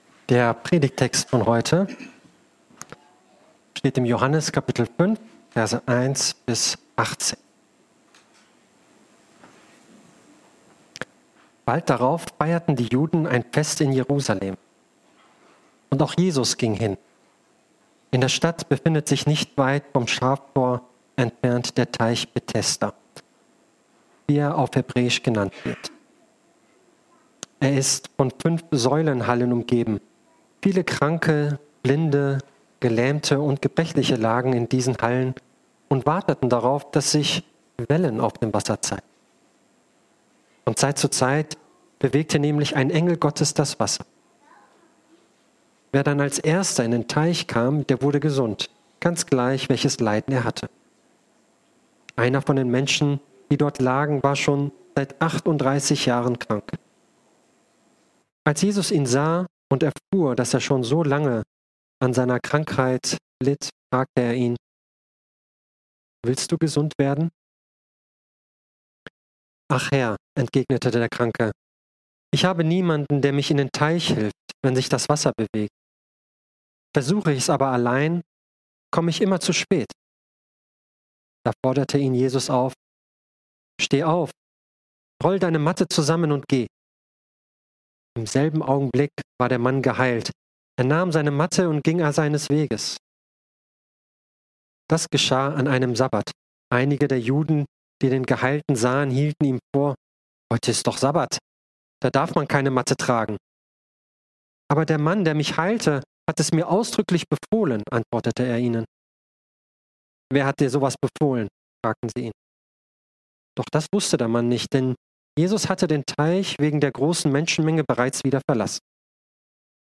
Heilung - Jesus sieht Dich! ~ Predigt-Podcast von unterwegs FeG Mönchengladbach Podcast